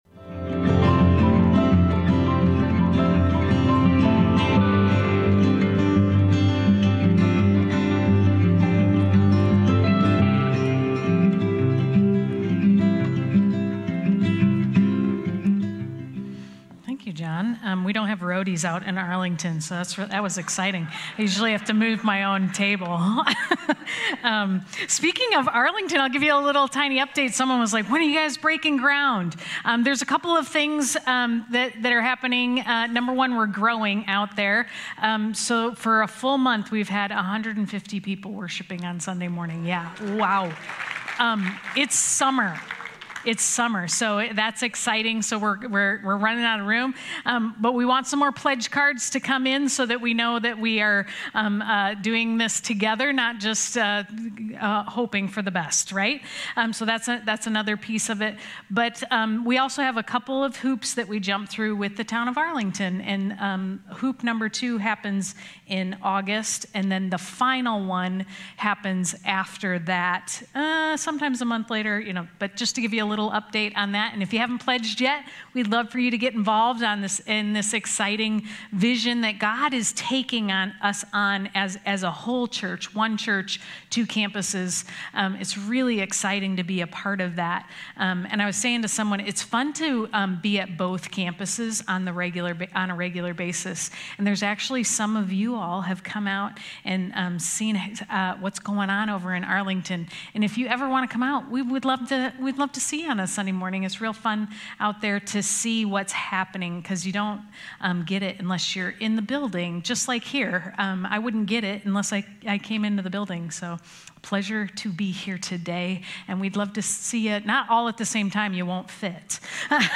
A message from the series "The Book of Ephesians."